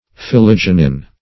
Search Result for " phillygenin" : The Collaborative International Dictionary of English v.0.48: Phillygenin \Phil*lyg"e*nin\, n. [Phillyrin + -gen + -in.]